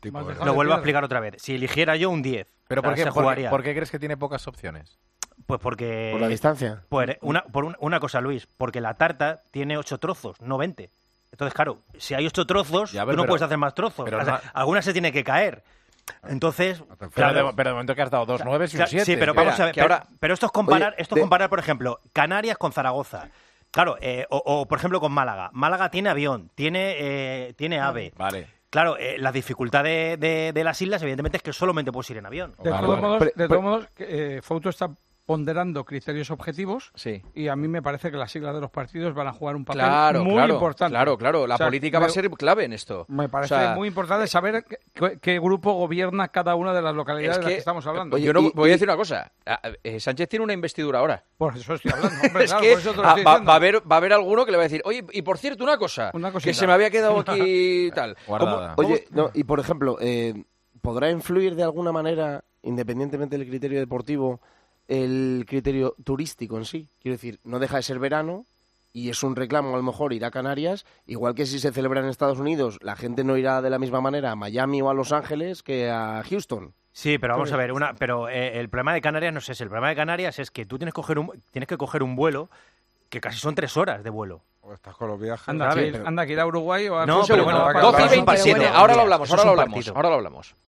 Los tertulianos de El Partidazo de COPE especularon con cuáles podrían ser las sedes de España en el campeonato de 2030 y creen que la política puede jugar un papel en ello.